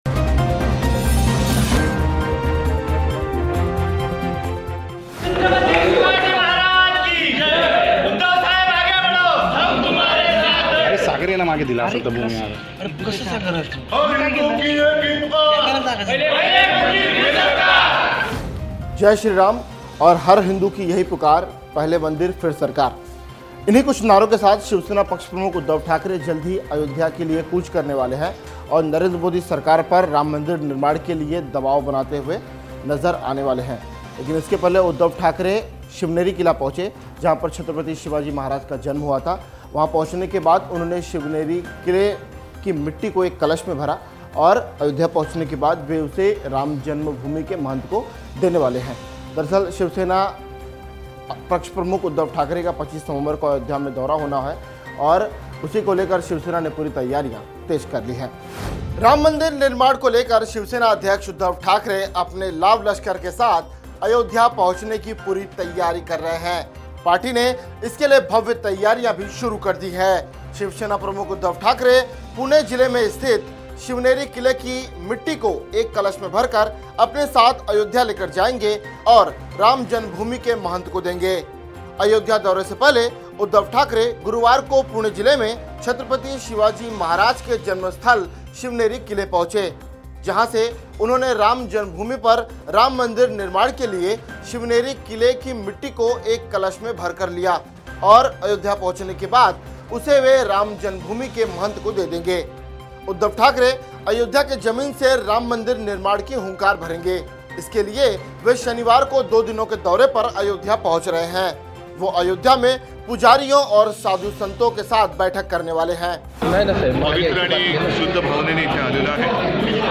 न्यूज़ रिपोर्ट - News Report Hindi / राम मंदिर अयोध्या चले शिवसेना उद्धव ठाकरे, बवाल नहीं होगा !